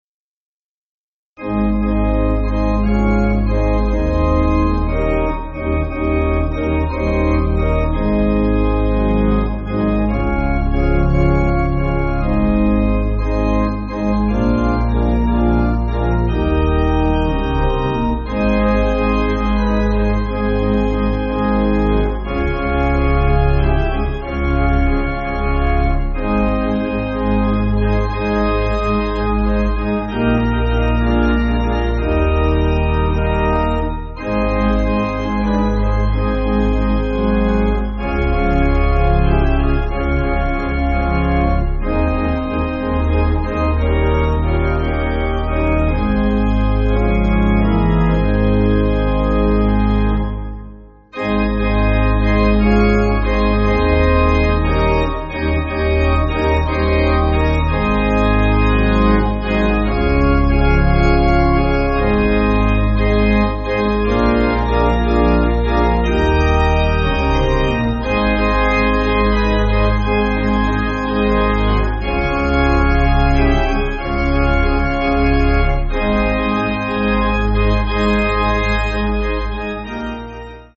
Organ
(CM)   2/Ab